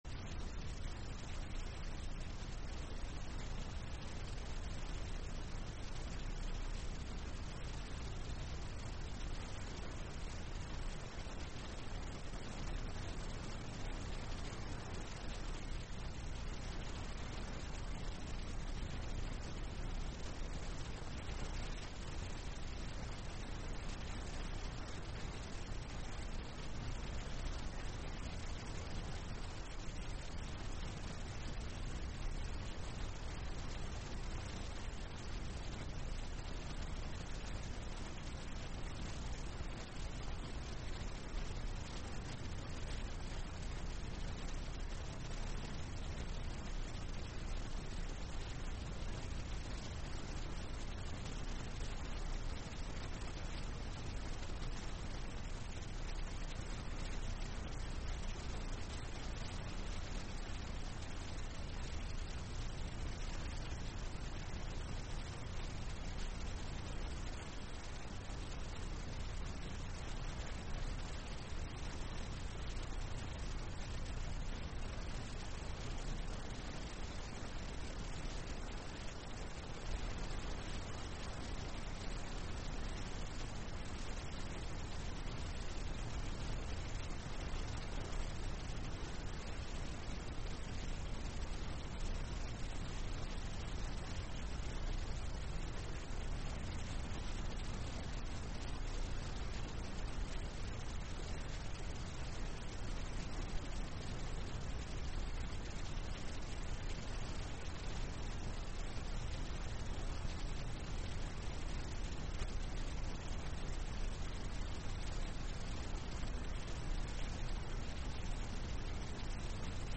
Sessão Ordinária da Câmara de Vereadores de Hulha Negra Data: 17 de abril de 2025